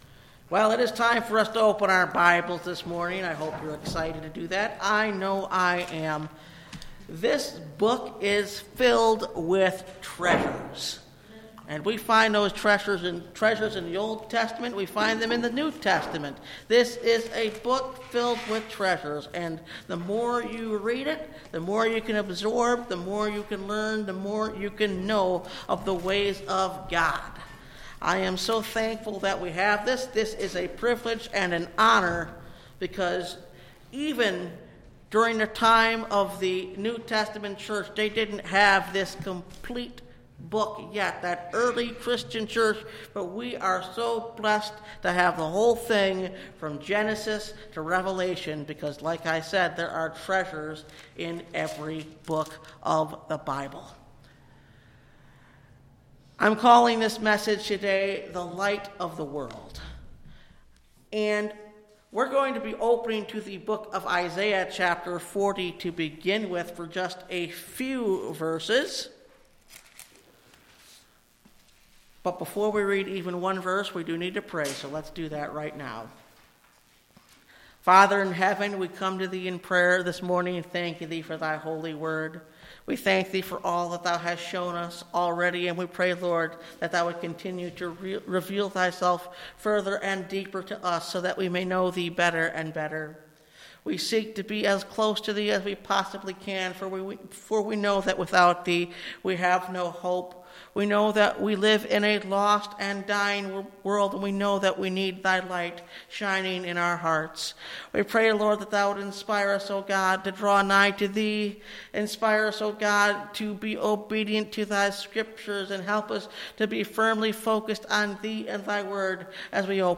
The Light Of The World (Message Audio) – Last Trumpet Ministries – Truth Tabernacle – Sermon Library